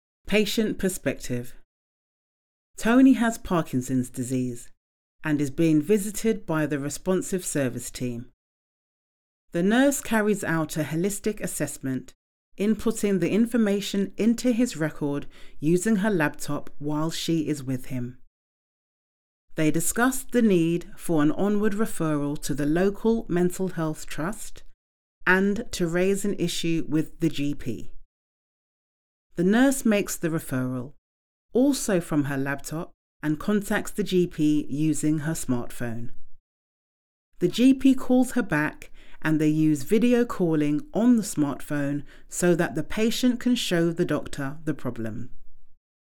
This is an NHS voiceover example